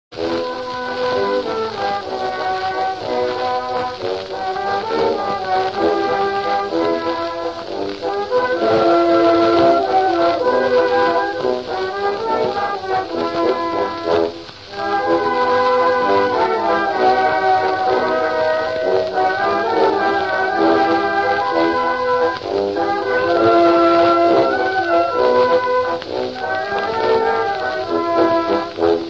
Вальс «Дачные грёзы» (главная музыкальная тема)